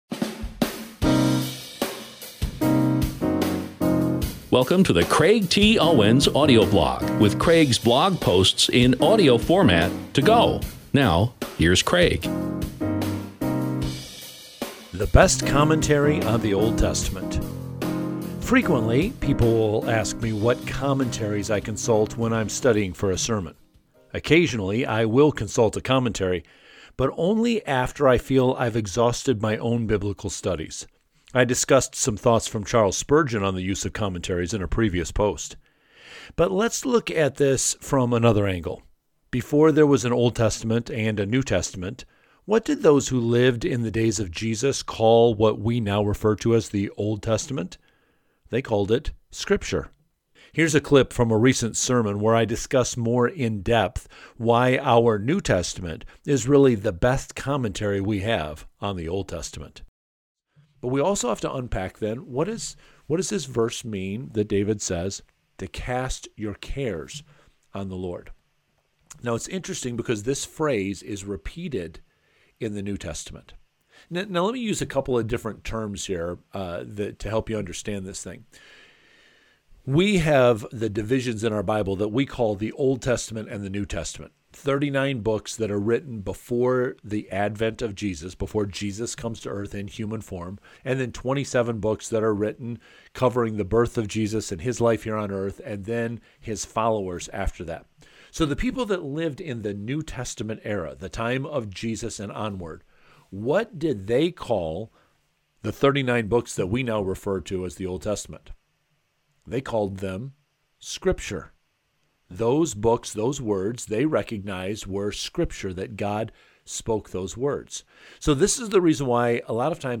Here’s a clip from a recent sermon where I discuss more in-depth why our New Testament is really the best commentary we have on the Old Testament: